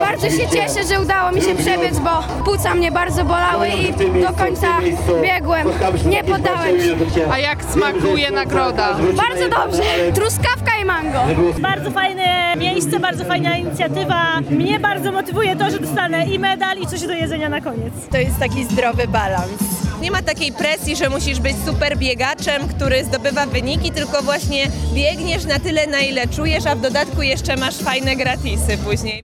Po raz szósty odbył się dzisiaj (12 maja) najsłodszy bieg w Łodzi - Ice Cream Run.
ice-cream-run-uczestnicy.mp3